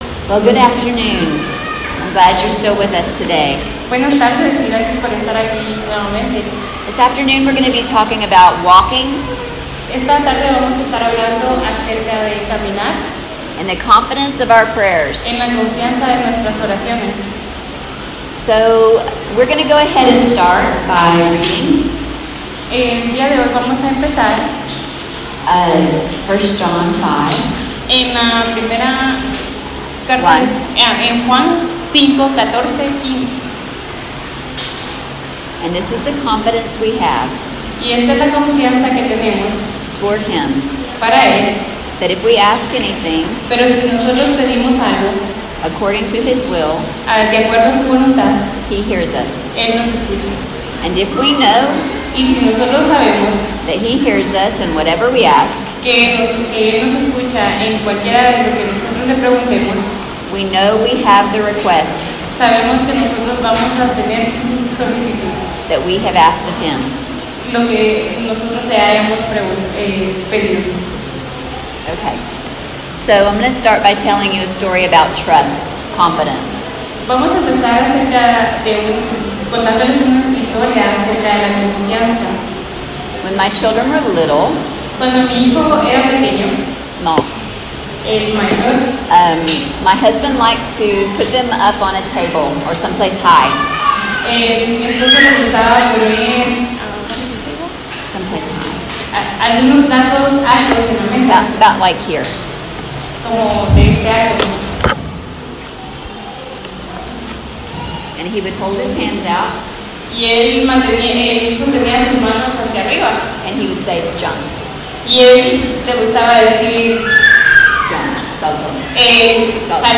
by admin | Apr 28, 2019 | ITL Lectureship 2019, Lectureships/Seminarios, Sermon